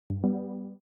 join_call-DlMV9nHk.ogg